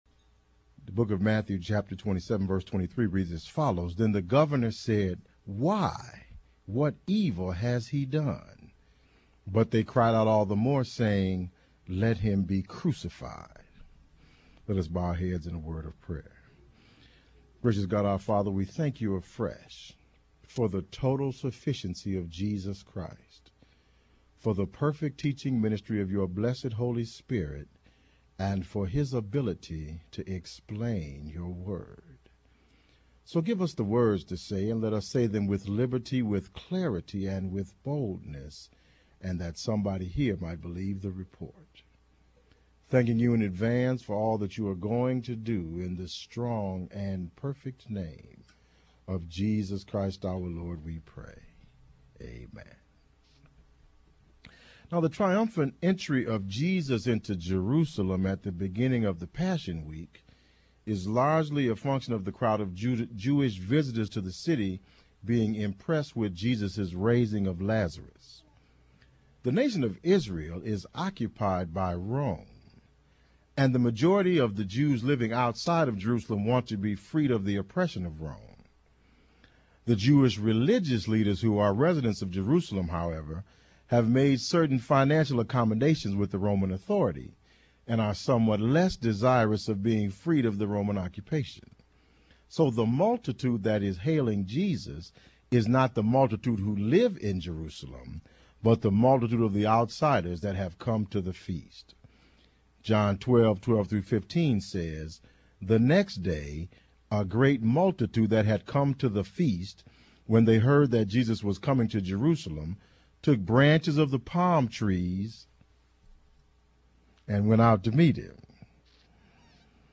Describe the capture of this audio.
Family Life Baptist Church : Let Him be Crucified